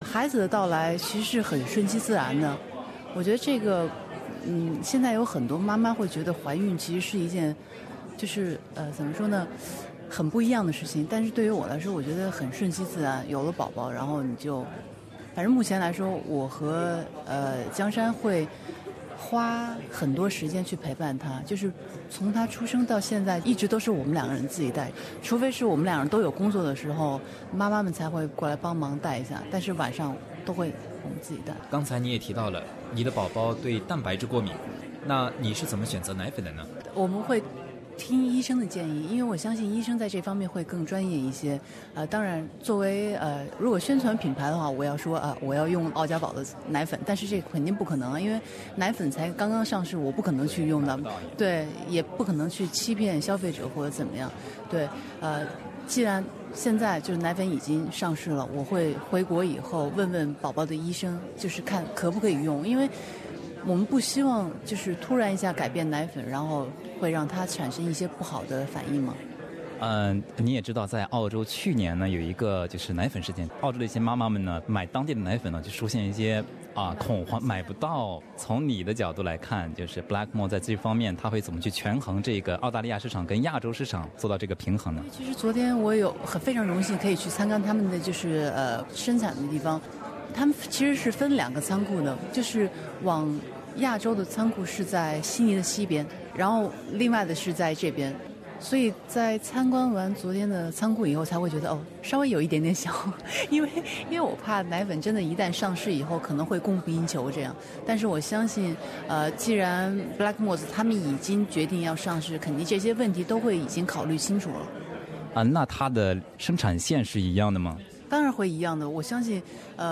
澳佳宝公司开始生产婴幼儿配方奶粉，并聘请世界著名网球运动员李娜为形象大使。李娜呢也是当妈妈不久，本台记者对她进行了采访，我们一起来听听，当妈妈后她的感受和遇到的一些问题以及对妈妈们的建议。